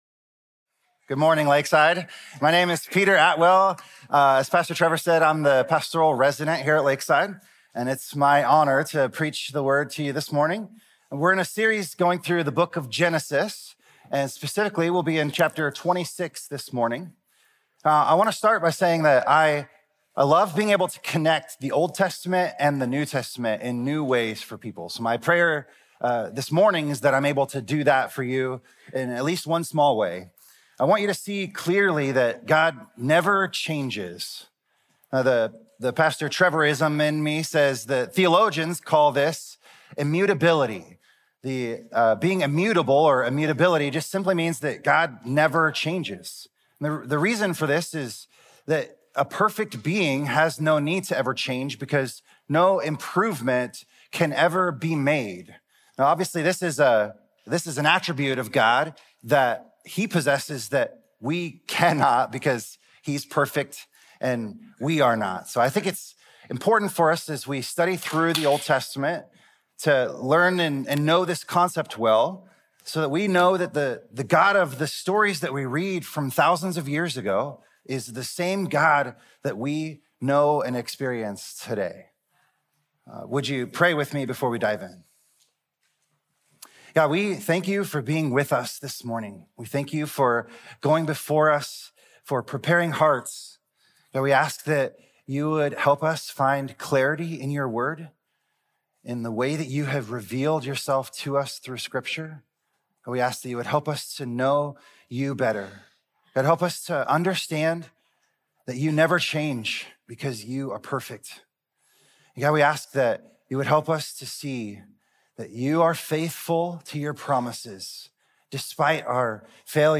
Sermons The World According to God